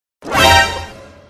Reactions
Sudden Suspense